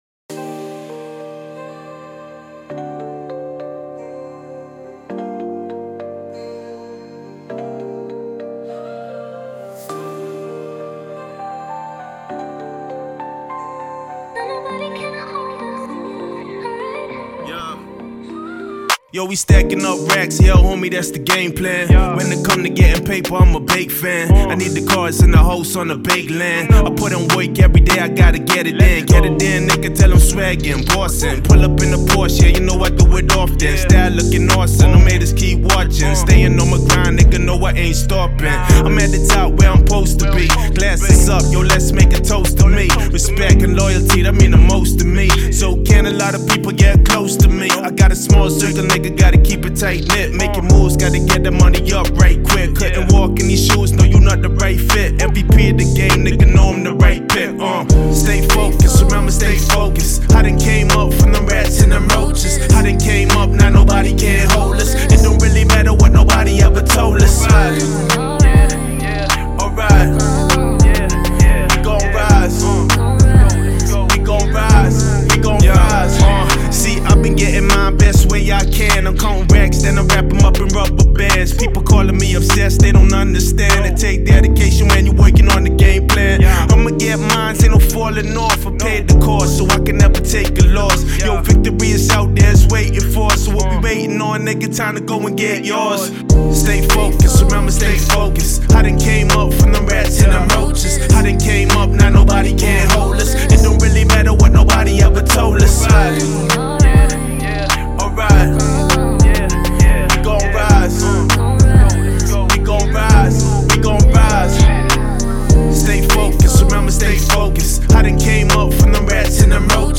Fully mixed acapella